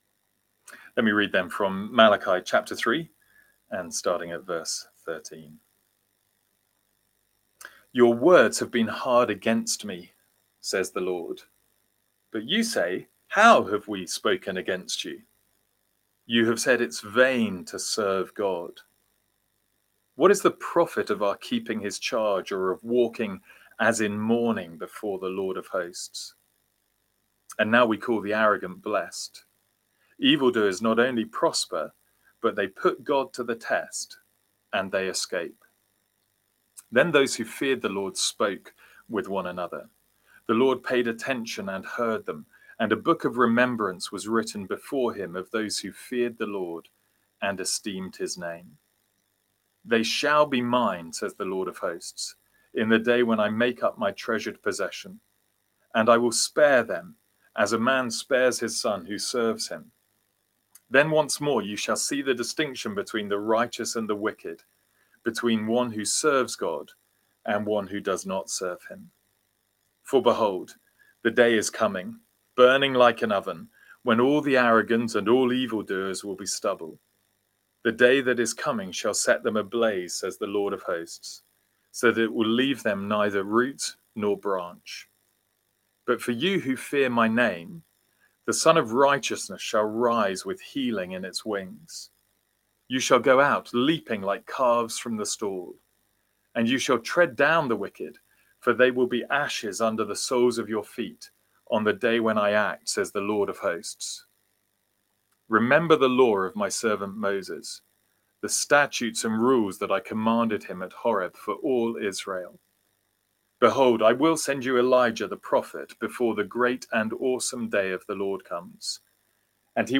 Sermons | St Andrews Free Church
From our evening series in Malachi.